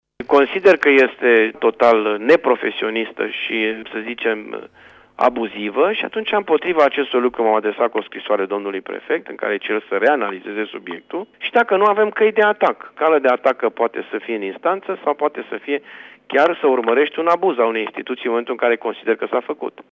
Primarul municipiului Tg.Mureș, Dorin Florea, consideră abuzivă măsura Prefecturii și spune că se va adresa instanței, dacă va fi cazul: